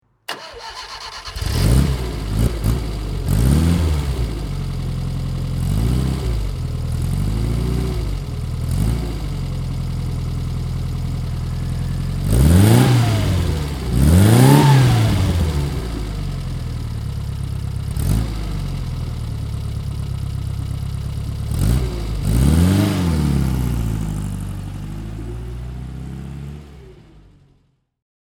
Moretti 850 Sportiva (1968) - Starten und Leerlauf